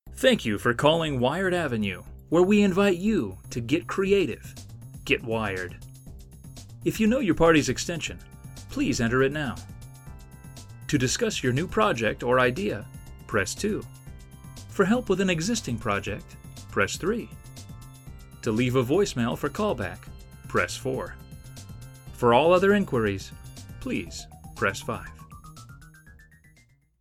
Male
Adult (30-50)
Company Phone Tree
0502Wired_Ave_PBX_with_music.mp3